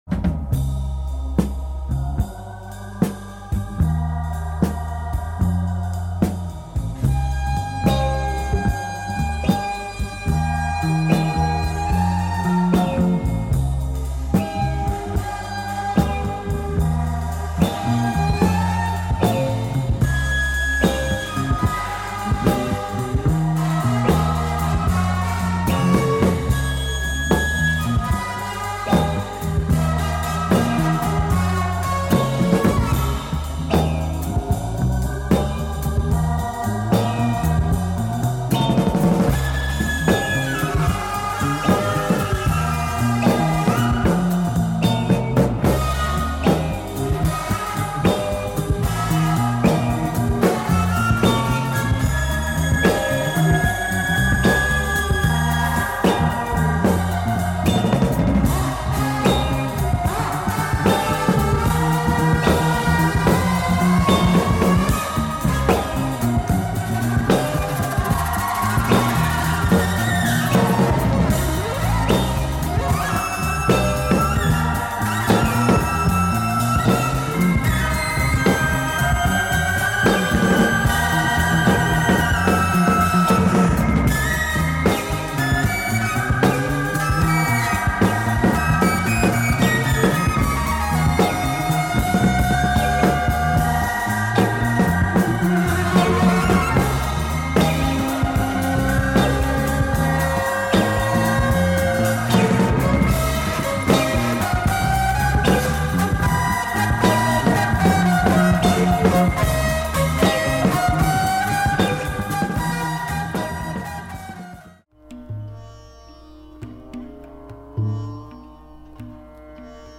Top French psychedelic jazz
sitar driven
Psych & Prog